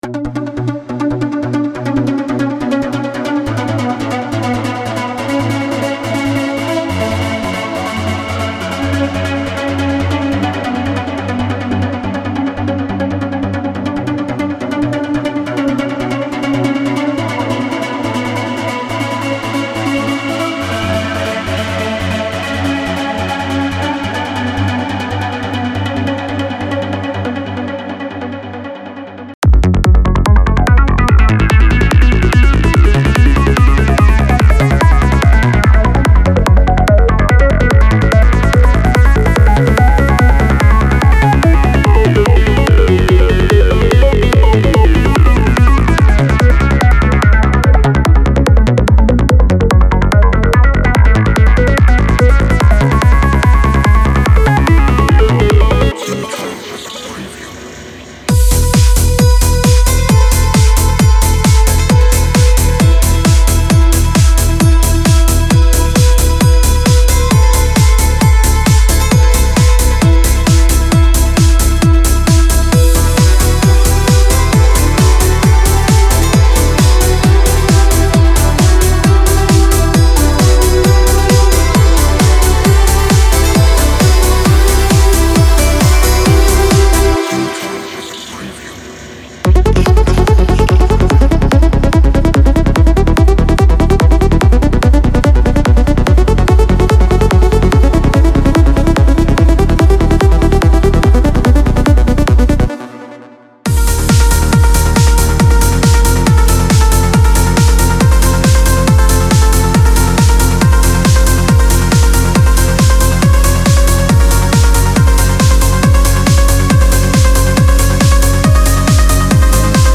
Cinematic / FX Psy-Trance Trance
Discover the crazy Sequences & ARPs, the mighty Leads & Plucks used from Classic Trance Tunes back in the 2000's. Don't miss CineTrance Connection Vol.4 and make classic trance great again.
Genre: Trance, Psy-Trance, Electronic, Cinematic